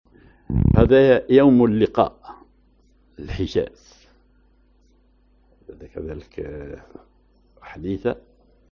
Maqam ar حجاز
genre أغنية